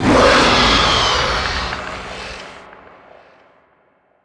c_alien_bat1.wav